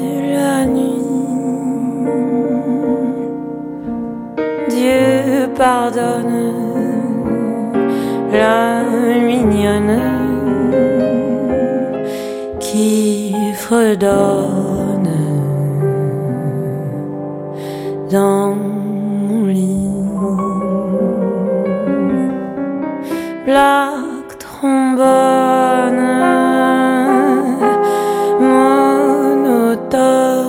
versions instrumentales en piano solo
piano/voix
Jazz